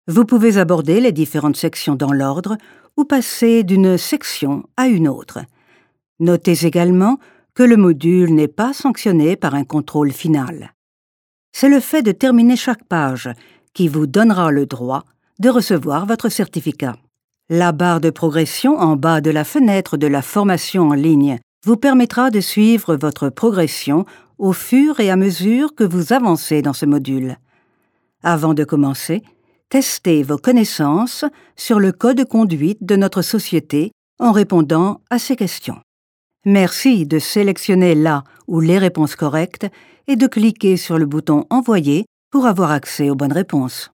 Erfahrene warme französische Stimme.
Kein Dialekt
Sprechprobe: Industrie (Muttersprache):